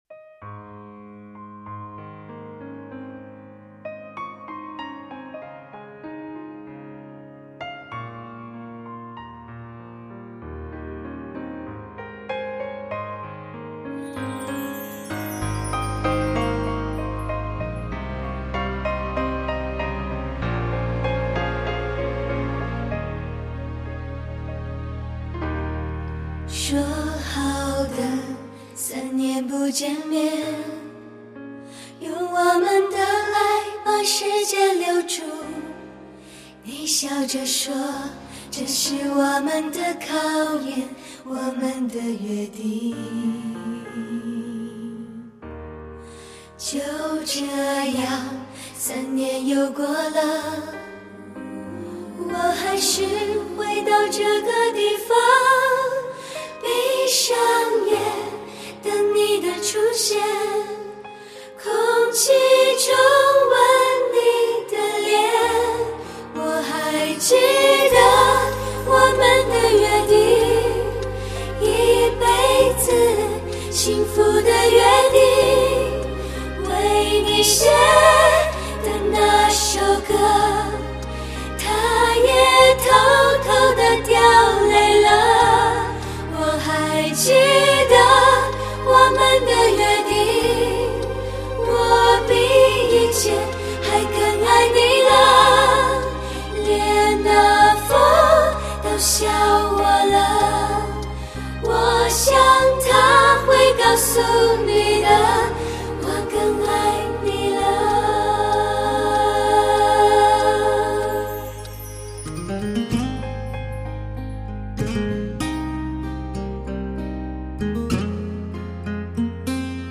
娇美纯净韵味盎然，一种脱胎换骨的演绎，使原本的音乐更具感染力和震撼力